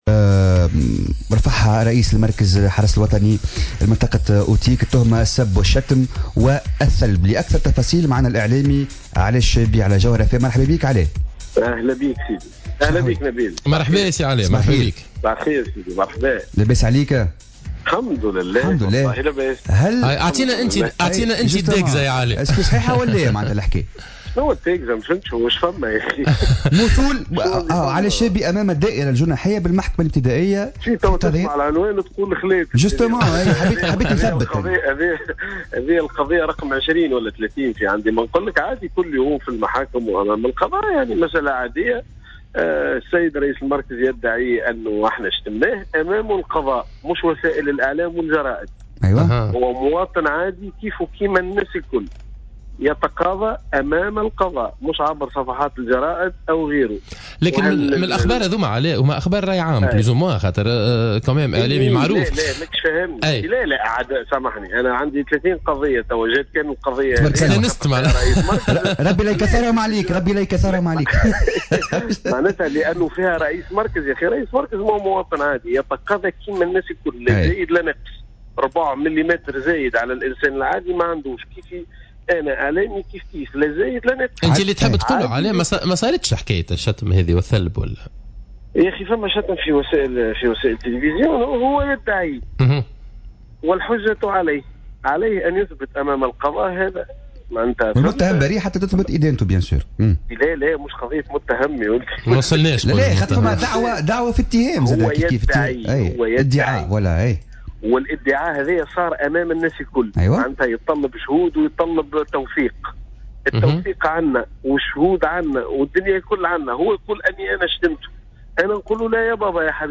قال الاعلامي علاء الشابي في مداخلة له على الجوهرة"اف ام" اليوم الأربعاء 13 جويلية 2016 أن مثوله أمس أمام الدائرة الجناحية ببنزرت أصبح مسألة عادية في برنامج"عندي ما نقلك" وهي القضية رقم 30 منذ بدء البرنامج على حد قوله.